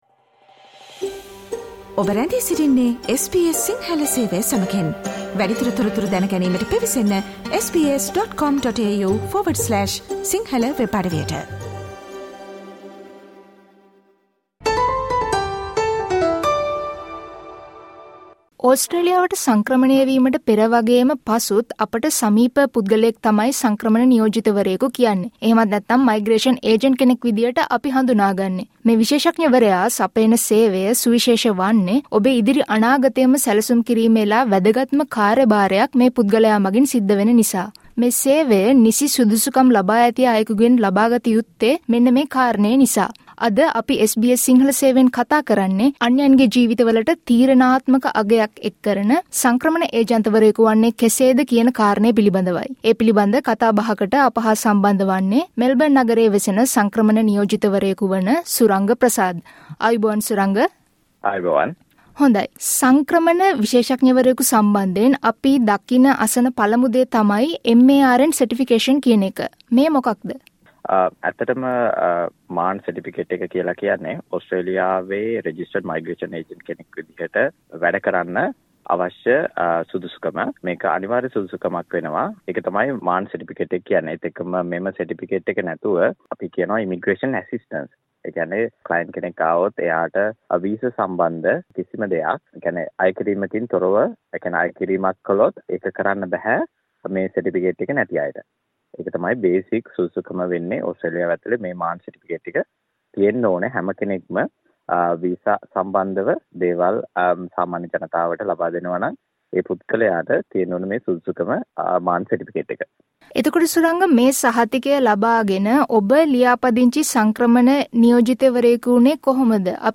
SBS සිංහල සේවය කළ සාකච්ඡාවට සවන් දෙන්න.